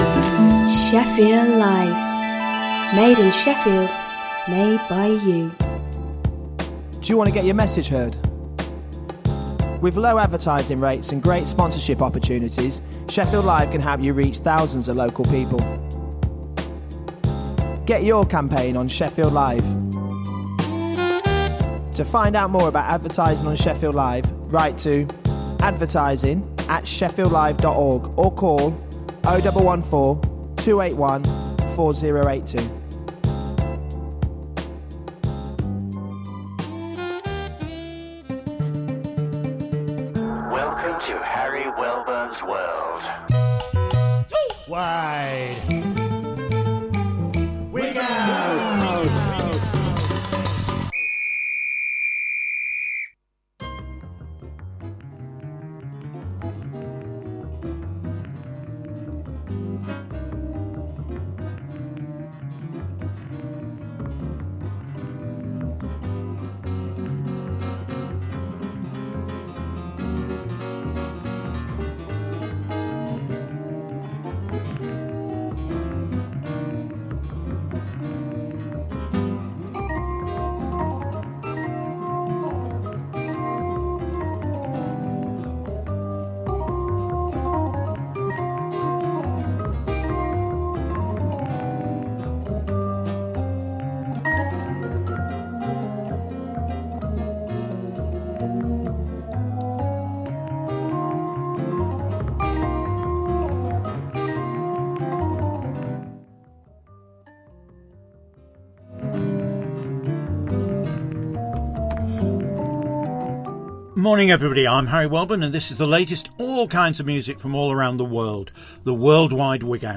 World Music to move & groove to.